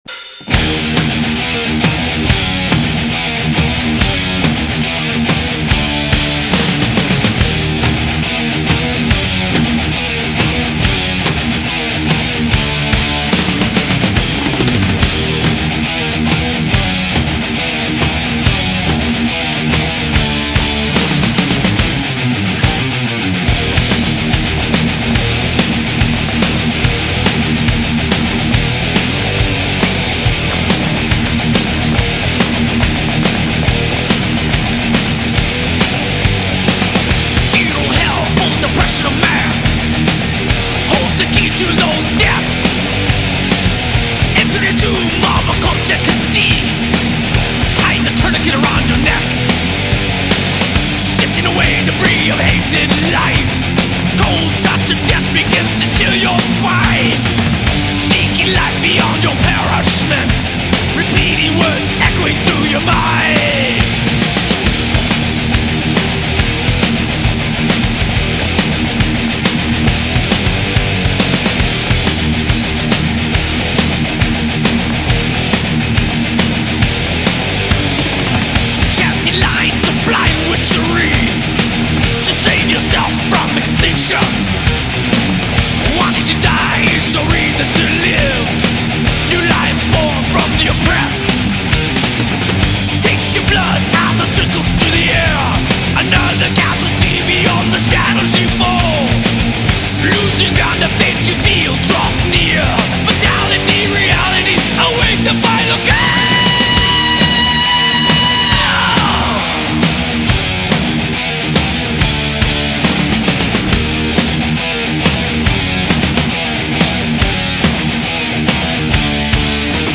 Жанр: Thrash, Speed Metal